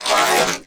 ALIEN_Communication_12_mono.wav